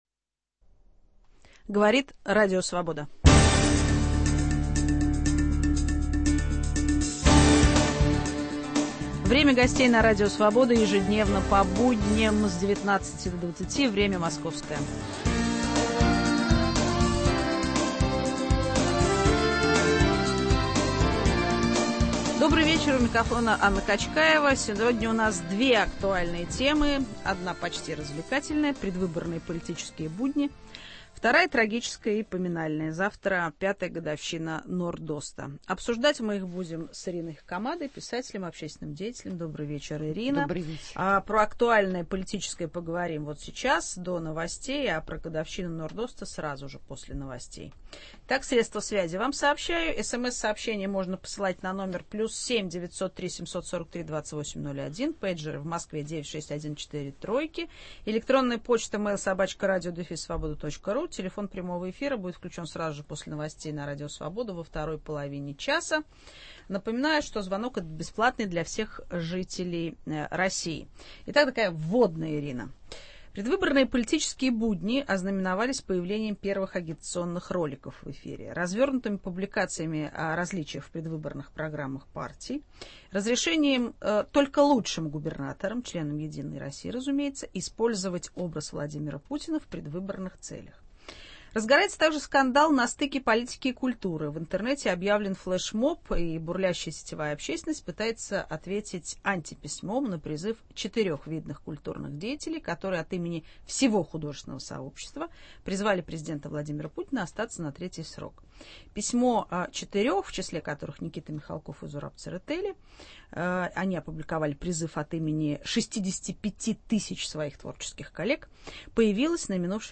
Предвыборные политические будни и пятую годовщину «Норд-Оста» обсуждаем с писателем и общественным деятелем Ириной Хакамадой.